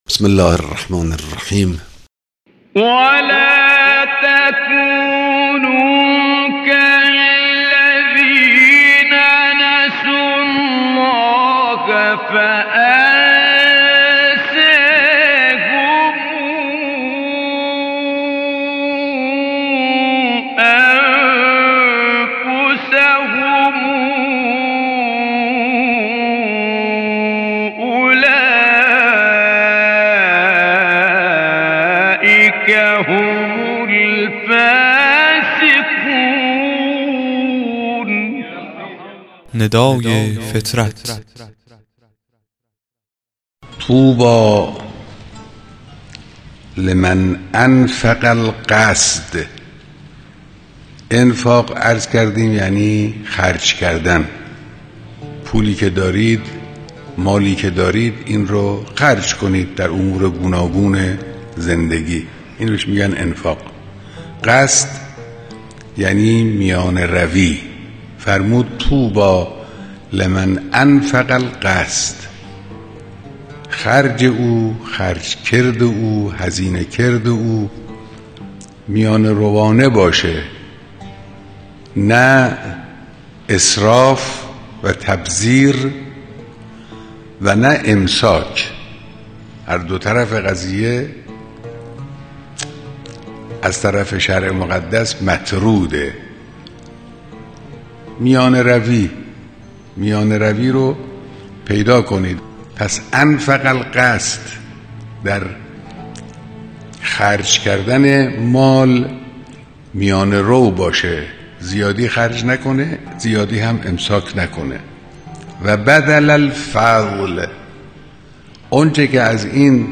قطعه صوتی کوتاه و زیبا از امام خامنه ای